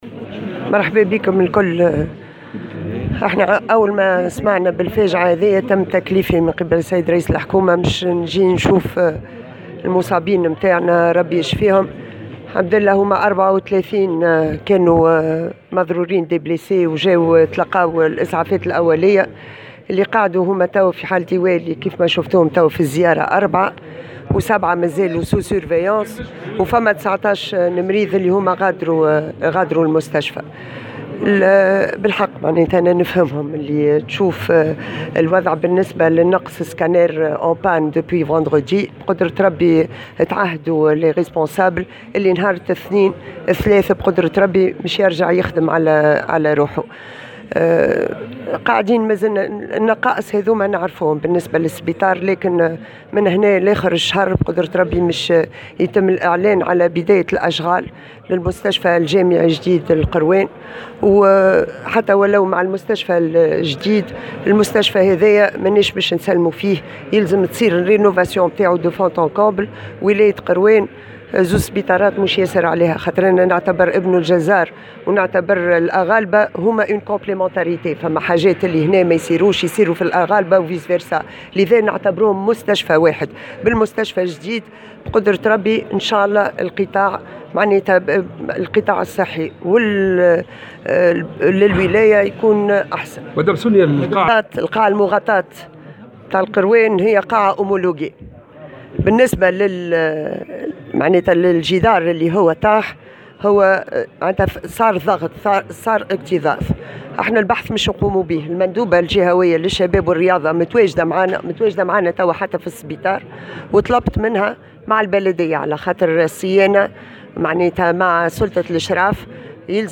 واضافت في تصريح لمراسل "الجوهرة اف أم" بالقيروان على هامش زيارة أدتها إلى مستشفى الأغالبة أن 7 مصابين مازالوا تحت المراقبة الطبية فيما تم ايواء 4 مصابين آخرين مؤكدة ان 17 شخصا غادروا المستشفى.